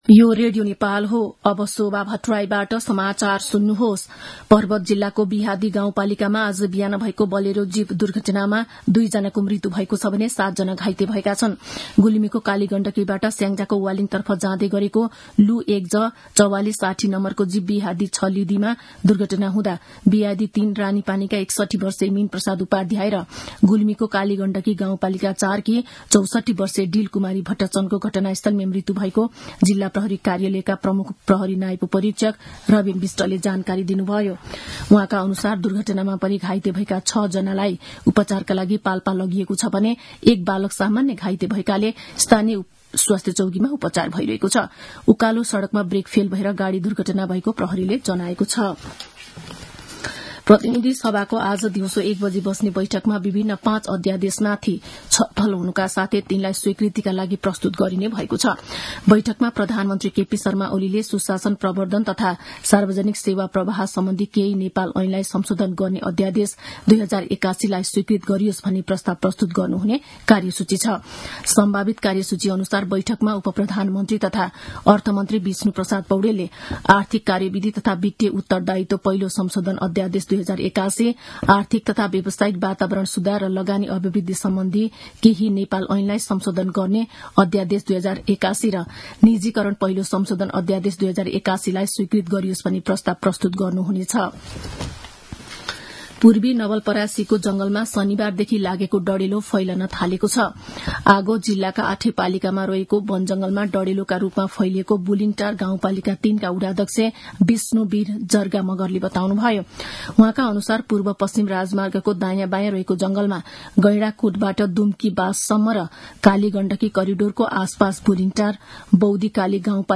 मध्यान्ह १२ बजेको नेपाली समाचार : २२ फागुन , २०८१